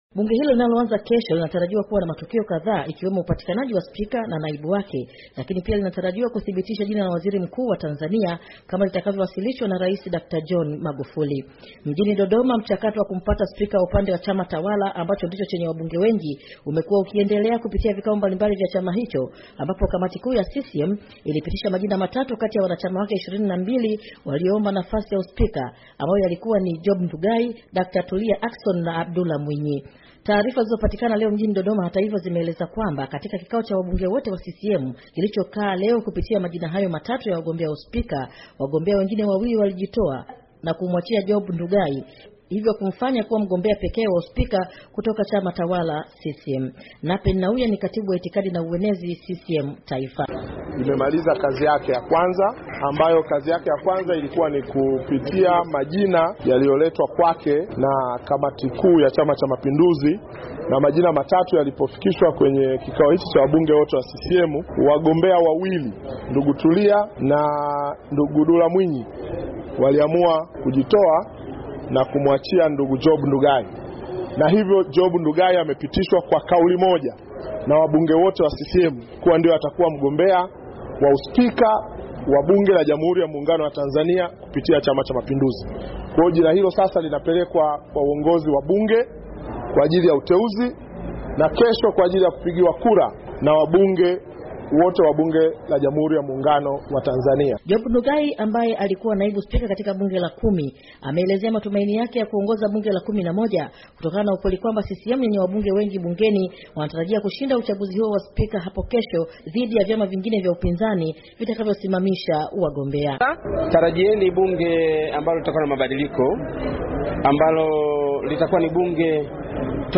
Ripoti
kutoka Dar es Salaam